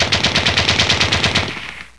43            <!-- Fire sound effect. -->
machngun.wav